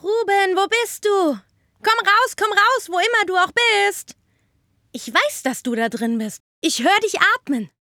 Synchron – Kinderstimme